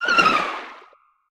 Sfx_creature_babypenguin_death_swim_02.ogg